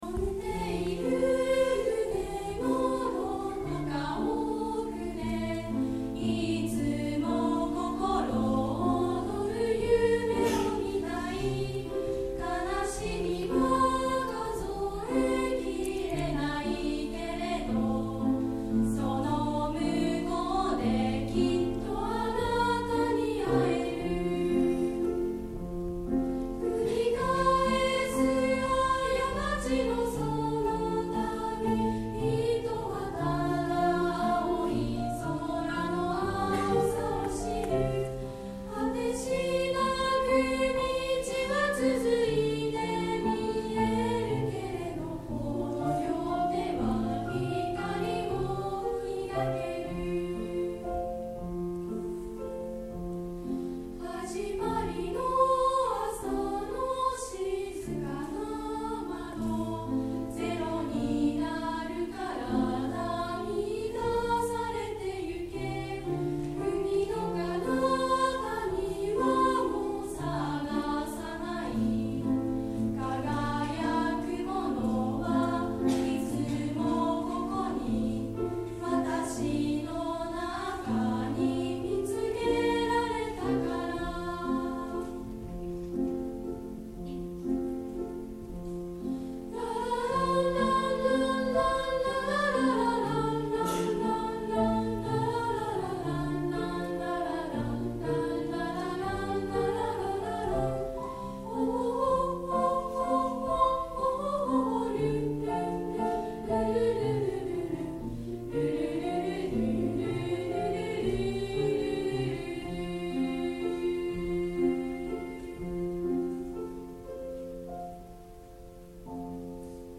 一人ひとりが輝いた文化祭 その一場面である音楽部の歌声をお届けします。 音楽部「いつも何度でも」